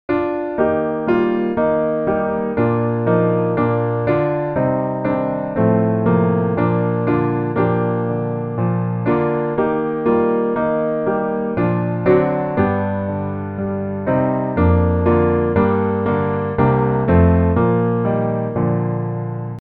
Gospel
Bb Major